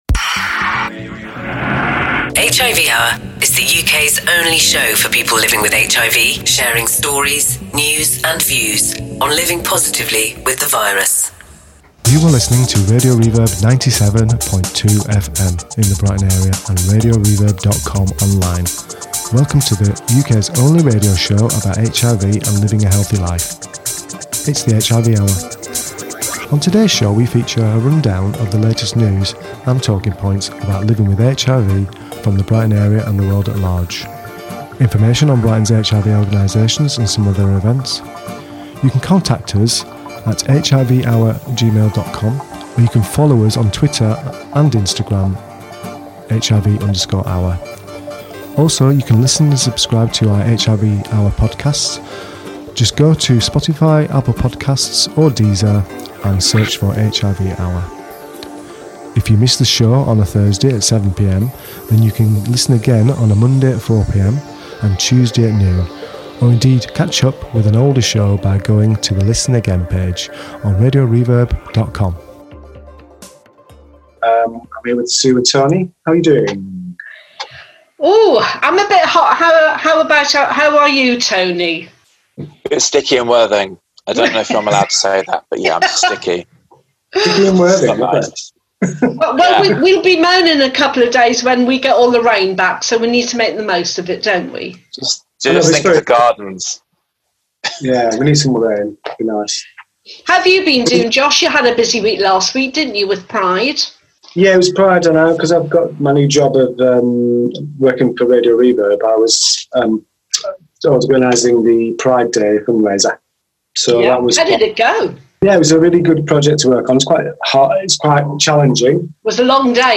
On the Show - A fantastic heartfelt Interview